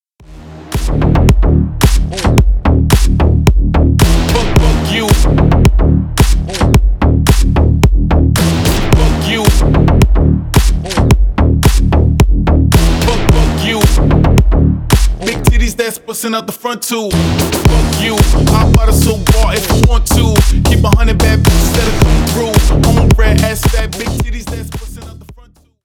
басы , громкие
хип-хоп